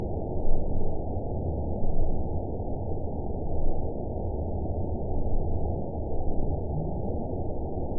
event 910684 date 01/29/22 time 01:56:21 GMT (3 years, 10 months ago) score 6.30 location TSS-AB08 detected by nrw target species NRW annotations +NRW Spectrogram: Frequency (kHz) vs. Time (s) audio not available .wav